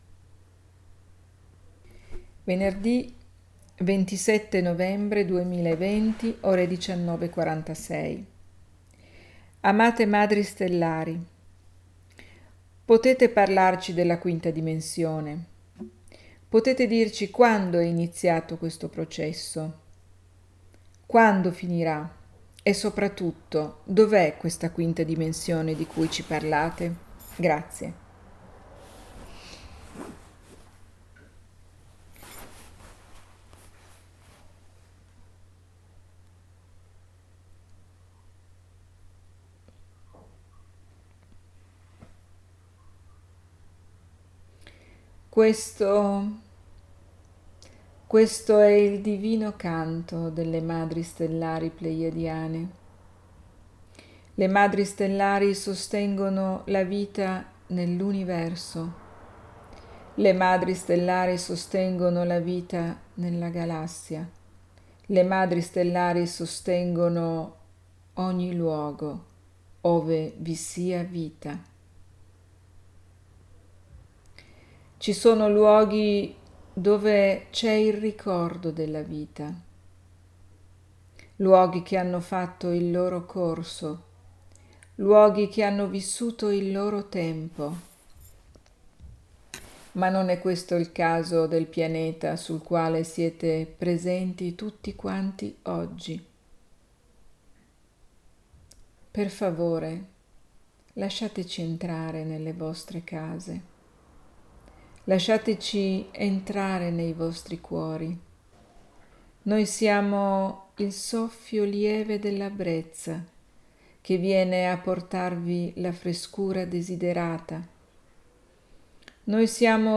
Venerd� 27 novembre 2020 nel webinar del gruppo fb Ponti di Luce
[l'audio della sessione di channeling]